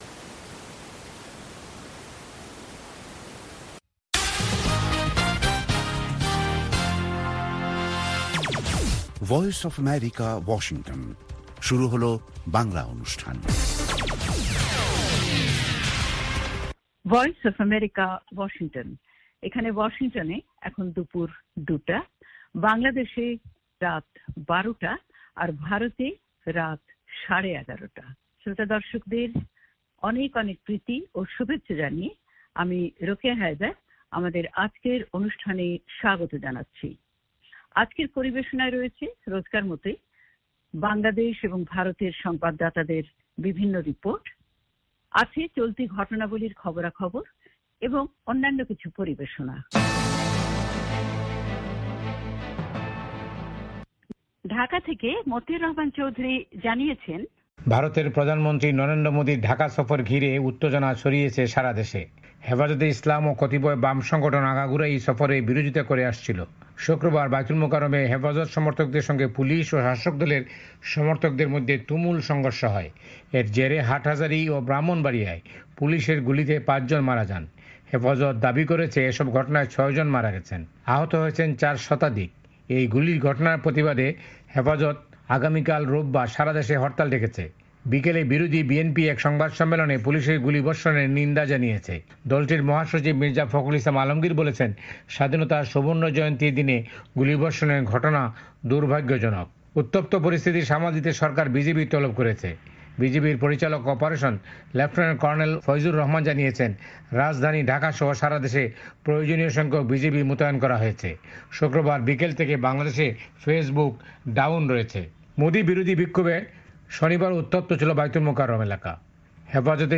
অনুষ্ঠানের শুরুতেই রয়েছে 👉বিশ্ব সংবাদ 👉উত্তপ্ত পরিস্থিতি সামাল দিতে বিজিবি তলব 👉বাংলাদেশ ও ভারতের মধ্যে দ্বিপাক্ষিক বাণিজ্যে ৫ টি সমঝোতা স্মারক সাক্ষর 👉 মোদী ভোটগ্রহণের সময় বাংলাদেশ সফরে গিয়ে ভোটের প্রচার করছেন অভিযোগ মমতার 👉 আবেদ খানের সাক্ষাৎকার 👉 মিতালী 👉 সম্পাদকীয়